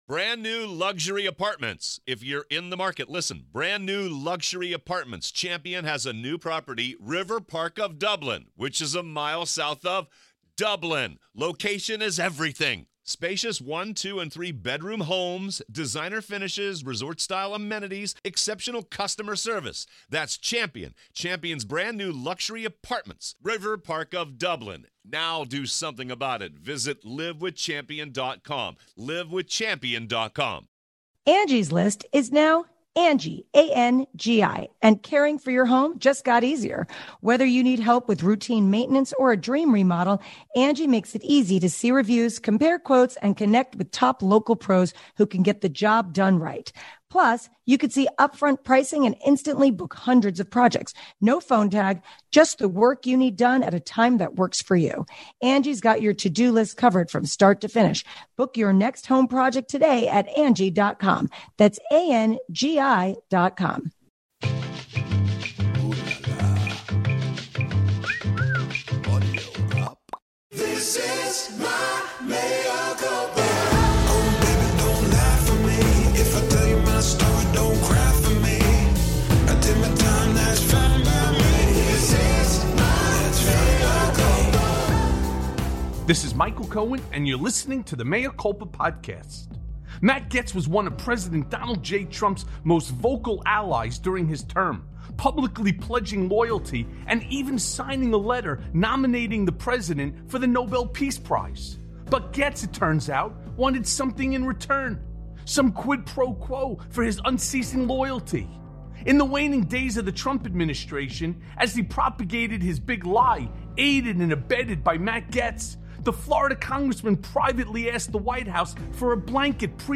Breaking!!! Matt Gaetz’s MAGA Sex Party + A Conversation With John Dean